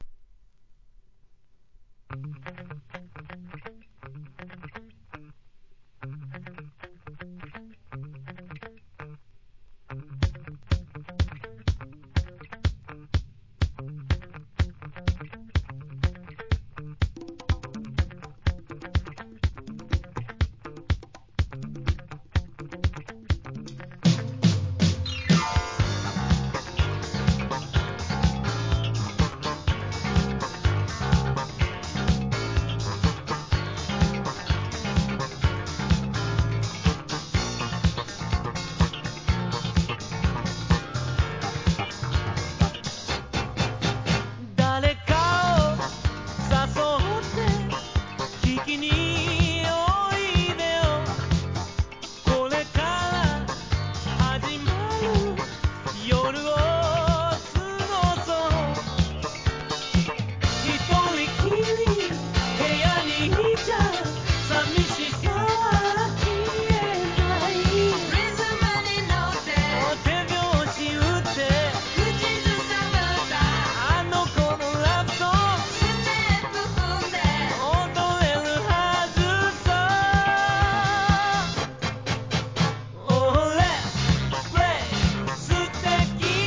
¥ 1,540 税込 関連カテゴリ SOUL/FUNK/etc...